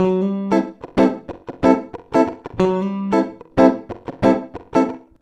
92FUNKY  5.wav